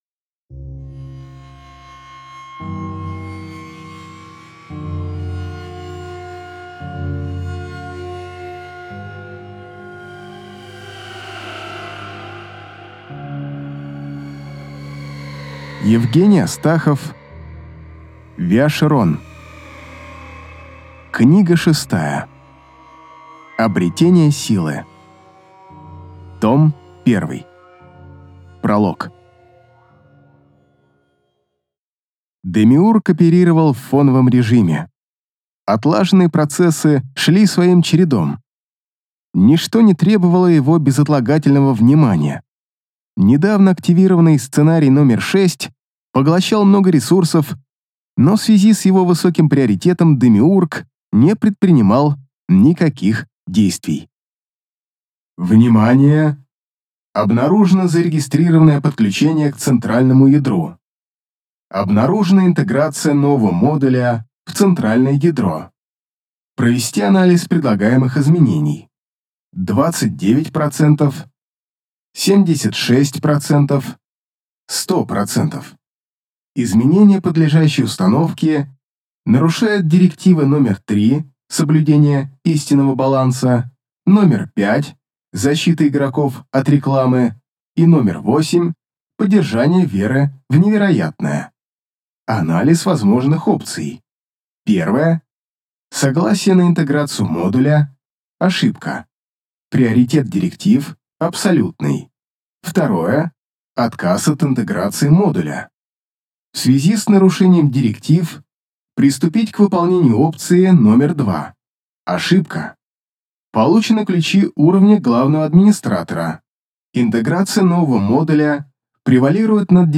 Аудиокнига Обретение Силы. Том I | Библиотека аудиокниг